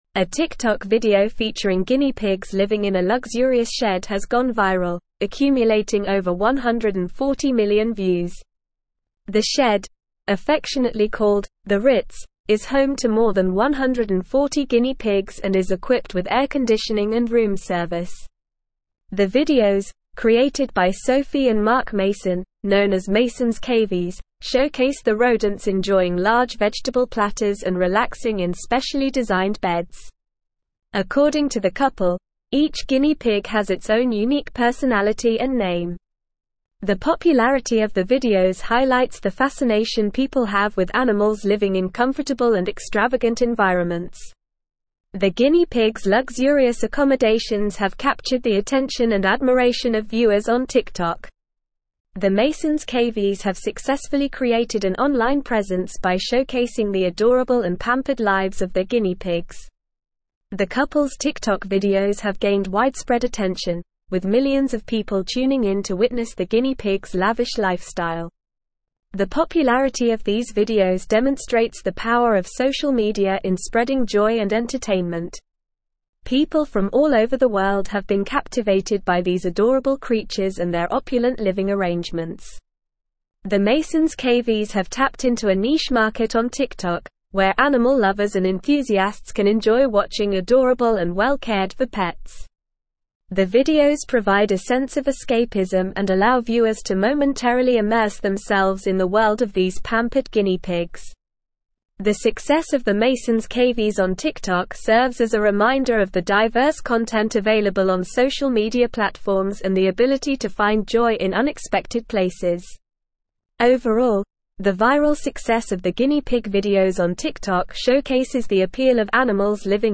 Normal
English-Newsroom-Advanced-NORMAL-Reading-Guinea-pigs-luxury-shed-goes-viral-on-TikTok.mp3